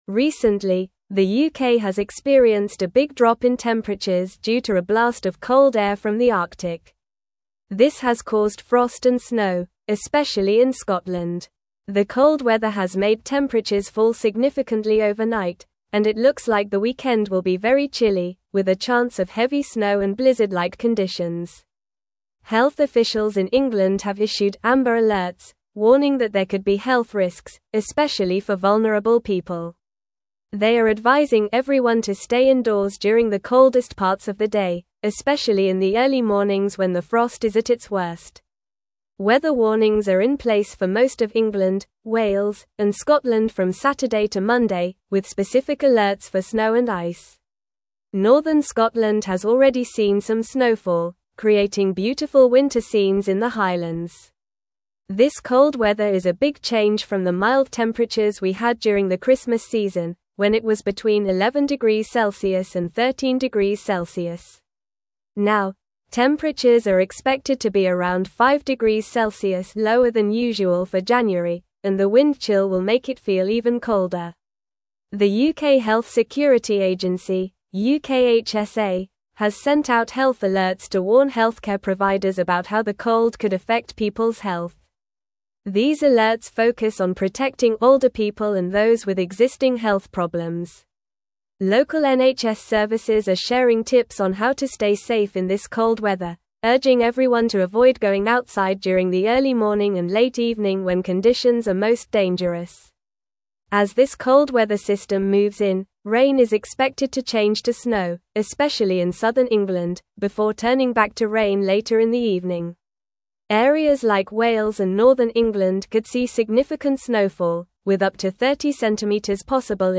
Normal
English-Newsroom-Upper-Intermediate-NORMAL-Reading-UK-Faces-Bitter-Cold-and-Heavy-Snow-This-Weekend.mp3